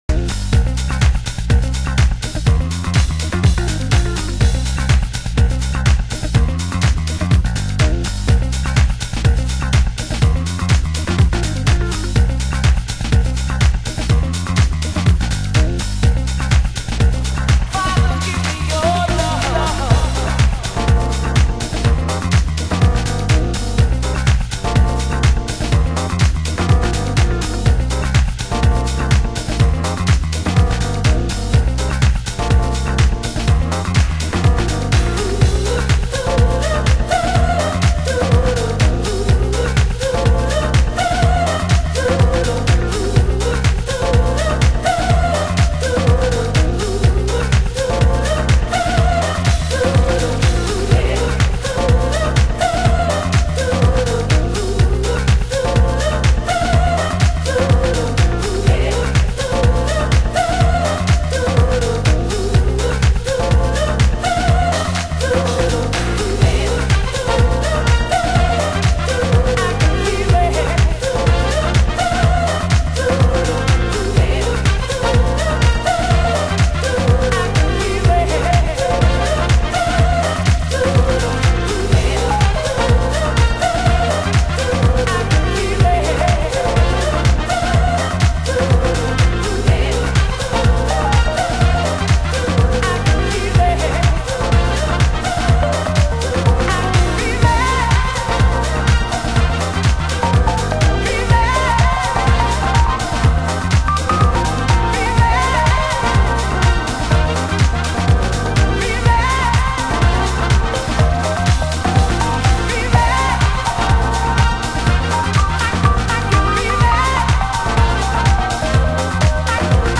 盤質：A面に軽いスレ傷有リ少しチリパチノイズ有　　　ジャケ：取り出し口にヨレ有